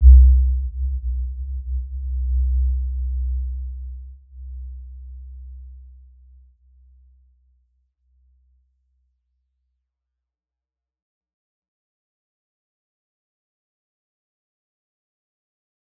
Warm-Bounce-C2-p.wav